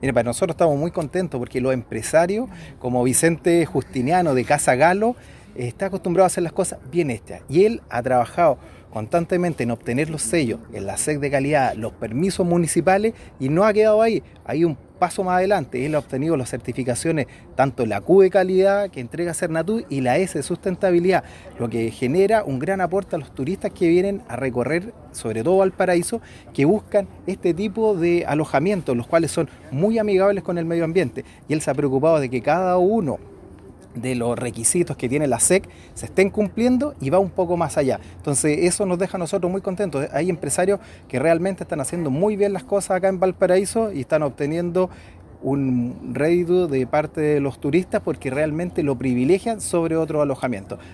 Director-Sernatur.mp3